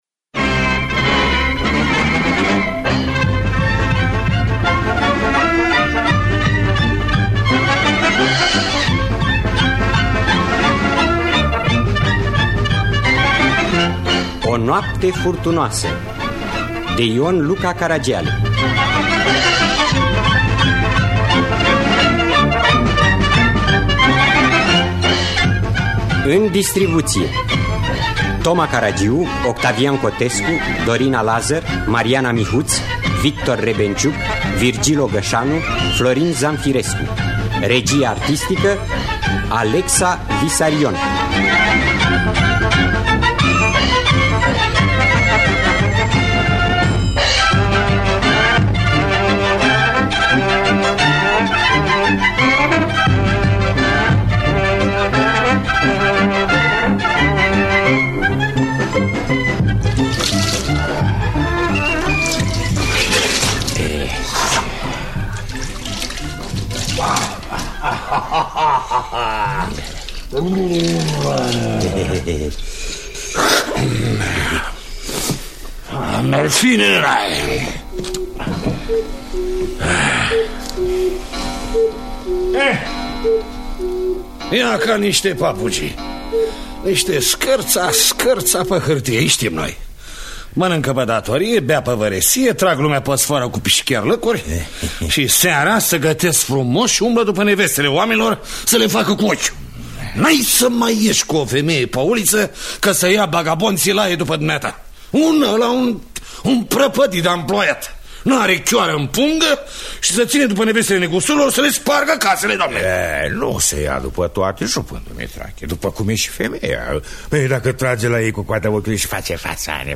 – Teatru Radiofonic Online
În distribuţie: Toma Caragiu, Octavian Cotescu, Victor Rebengiuc, Florin Zamfirescu, Virgil Ogăşanu, Dorina Lazăr, Mariana Mihuţ.